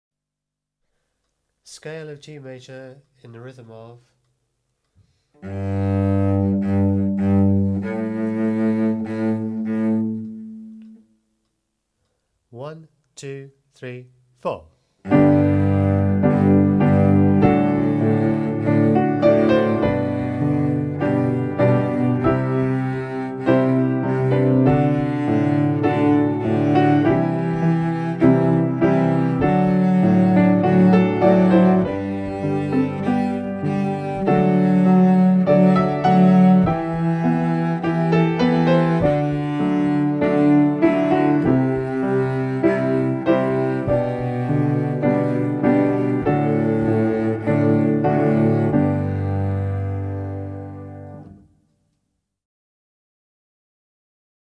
46 G-major scale (Cello)